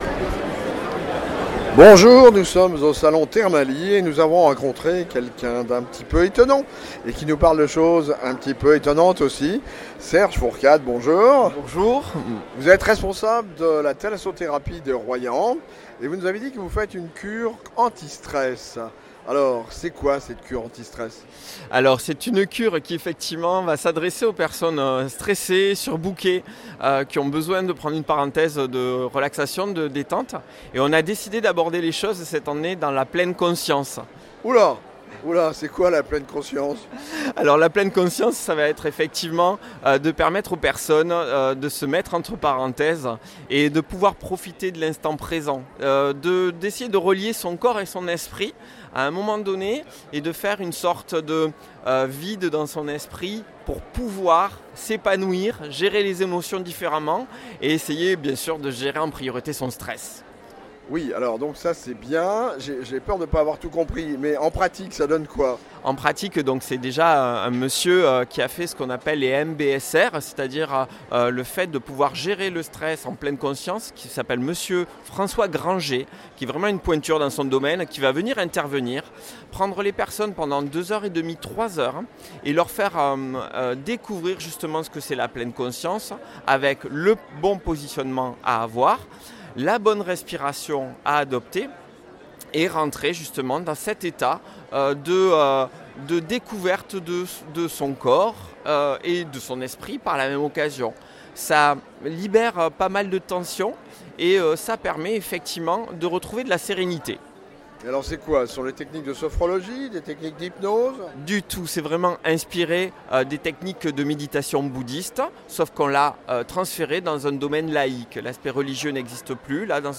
thermalies_royan_anti_stress.mp3